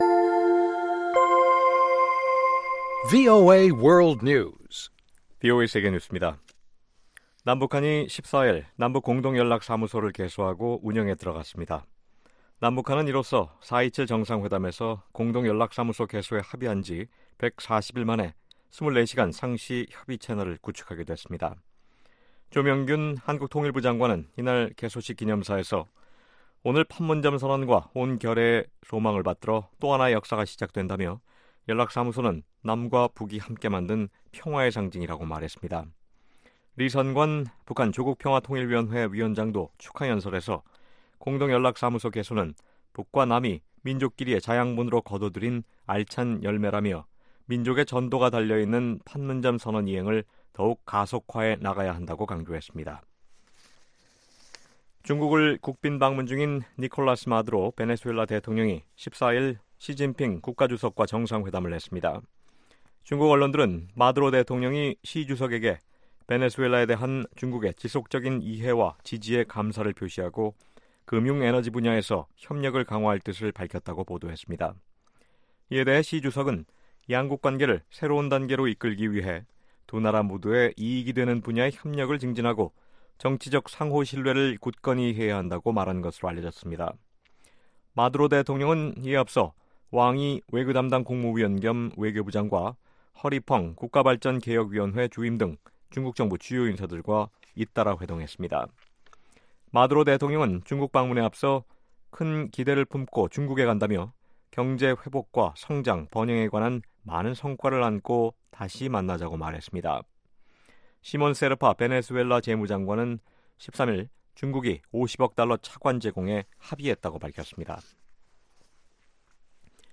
VOA 한국어 아침 뉴스 프로그램 '워싱턴 뉴스 광장' 2018년 9월 15일 방송입니다. 미국이 대북제재를 위반한 중국, 러시아 IT 회사를 제재 대상으로 지정했습니다.